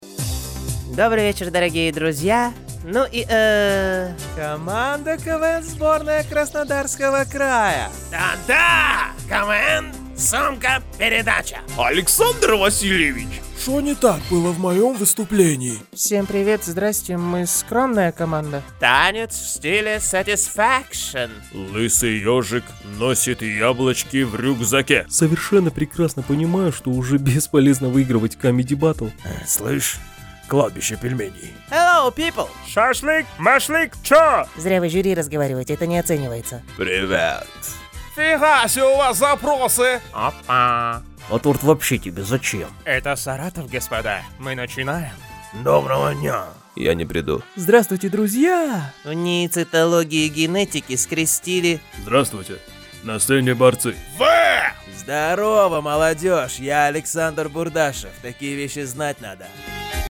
Муж, Пародия
Запись производится в студии, оборудование (Звук.карта, Микрофон, наушники- набор марки «Steinberg»).